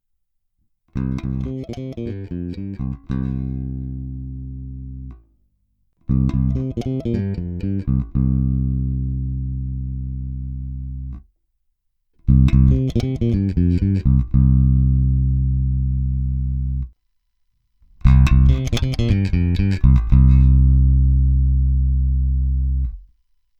Ukázky jsou nahrány rovnou do zvukovky, jednotlivé nahrávky jsou normalizovány, přičemž pasívní režim nebo aktivní režim se staženými (nepřidanými) korekcemi je co do síly signálu daleko slabší než při použití ekvalizéru, což samozřejmě tyto normalizované nahrávky nepostihnou.
Použité struny jsou dva roky staré ocelové pětačtyřicítky Elixiry.
Vliv korekcí na sílu signálu a barvu zvuku – hráno na oba snímače v pořadí: korekce na nule, přidány na 1/3, přidány na 2/3, přidány naplno.
Nenormalizováno